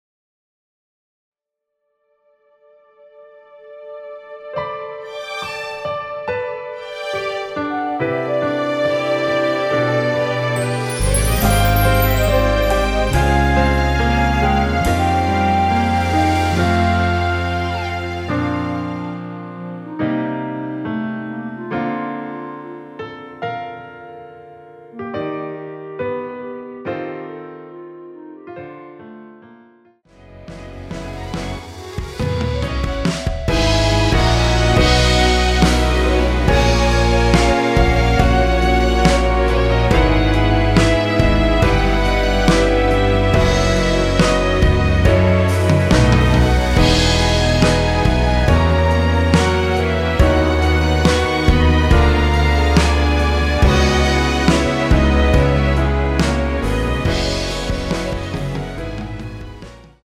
원키 멜로디 포함된 MR 입니다.(미리듣기 참조)
Bb
앞부분30초, 뒷부분30초씩 편집해서 올려 드리고 있습니다.
중간에 음이 끈어지고 다시 나오는 이유는